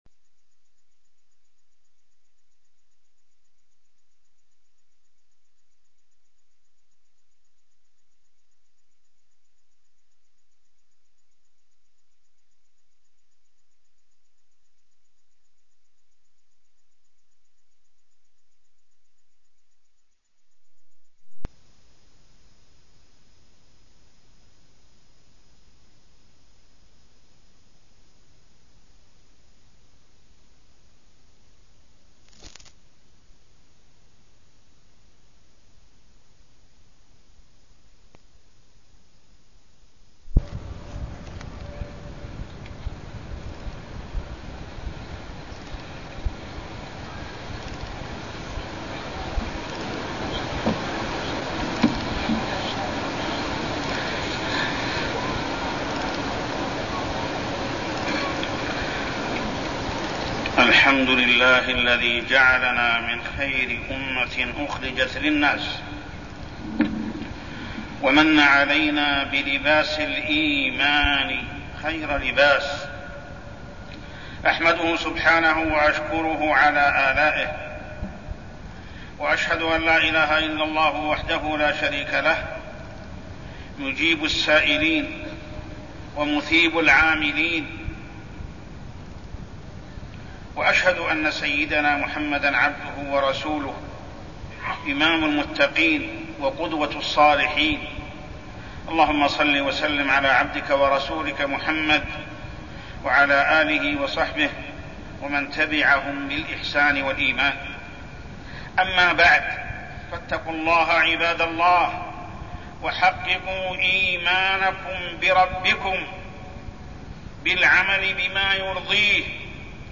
تاريخ النشر ٢١ رجب ١٤١٥ هـ المكان: المسجد الحرام الشيخ: محمد بن عبد الله السبيل محمد بن عبد الله السبيل الإيمان والعمل